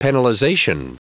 Prononciation audio / Fichier audio de PENALIZATION en anglais
Prononciation du mot penalization en anglais (fichier audio)